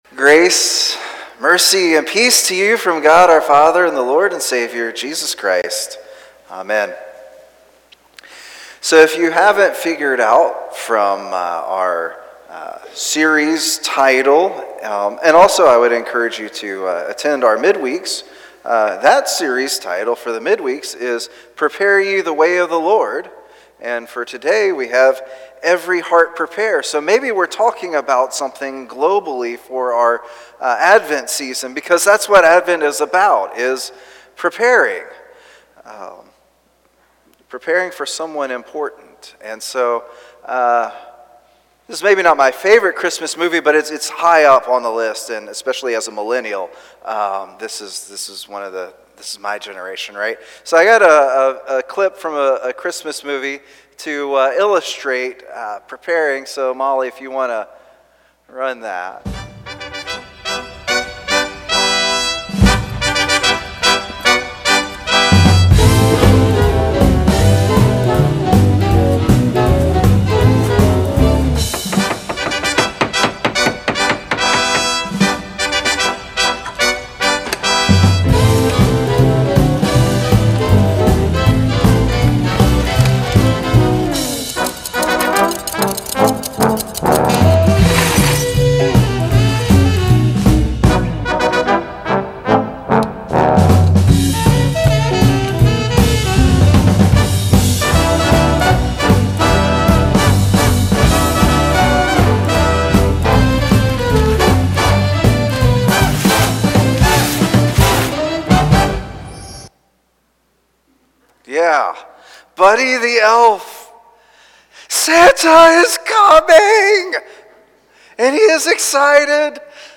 Passage: John 11:11-27 Service Type: Traditional and Blended